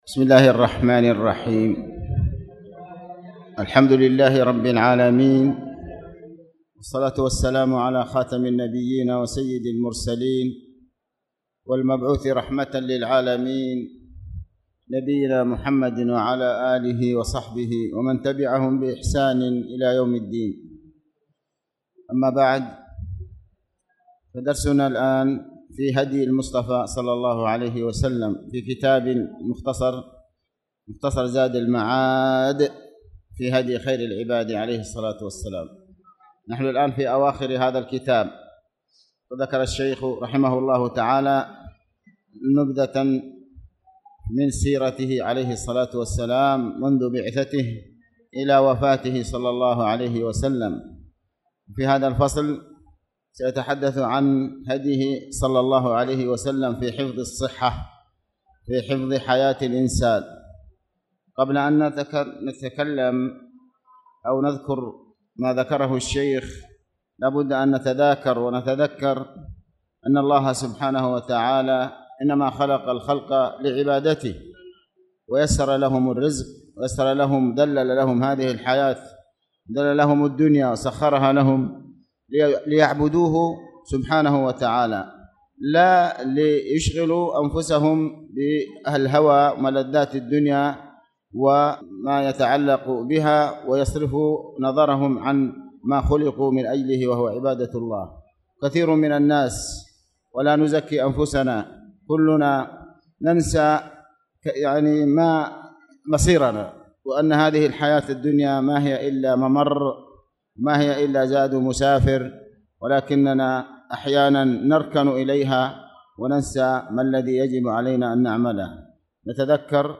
تاريخ النشر ١٢ شعبان ١٤٣٧ هـ المكان: المسجد الحرام الشيخ